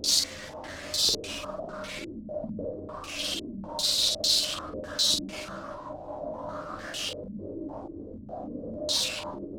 STK_MovingNoiseA-100_02.wav